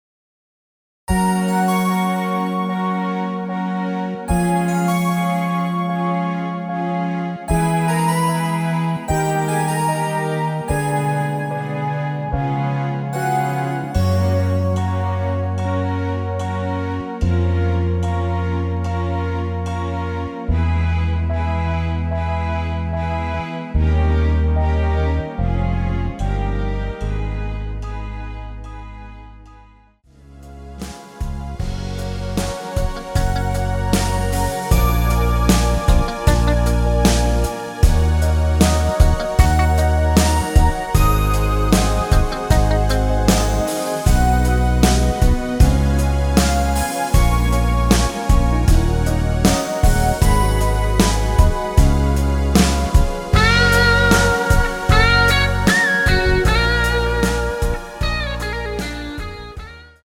원키에서(-1)내린 MR입니다.
Gb
앞부분30초, 뒷부분30초씩 편집해서 올려 드리고 있습니다.
중간에 음이 끈어지고 다시 나오는 이유는